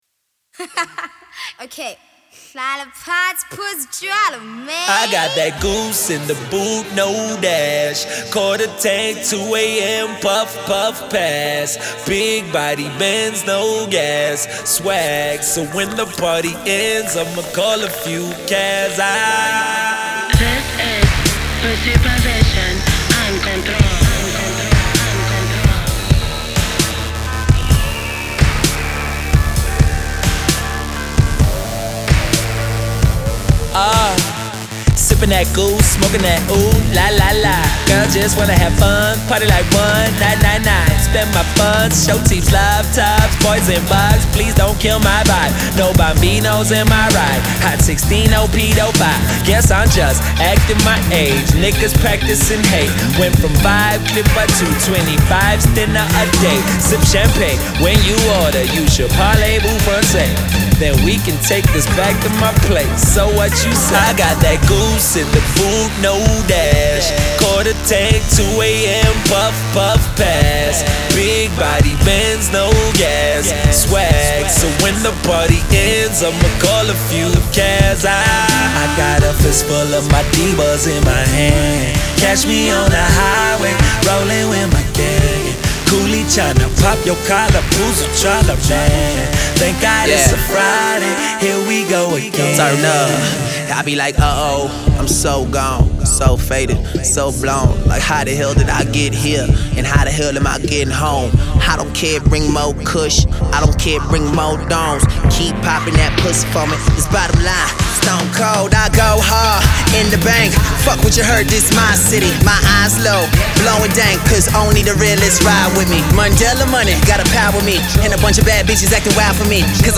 Hip-Hop
WARNING! Explicit lyrics